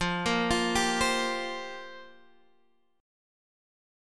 Fsus2sus4 chord